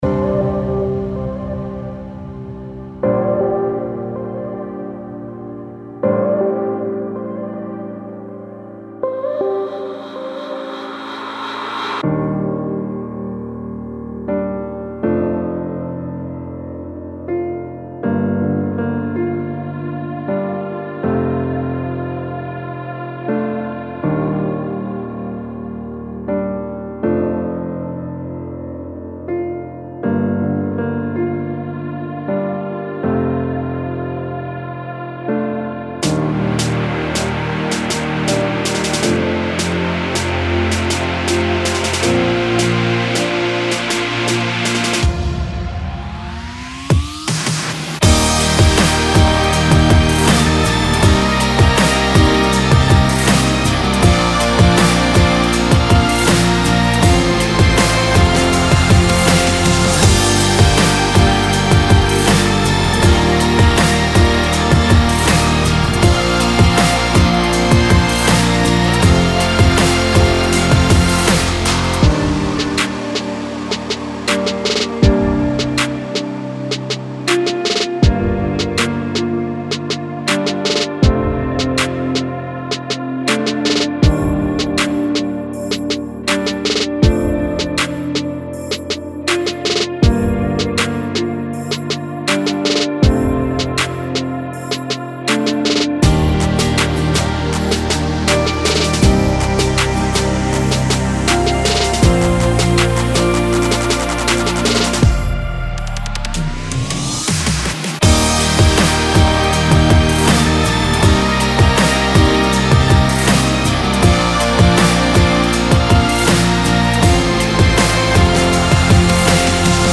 Темп песни: медленный.
• Минусовка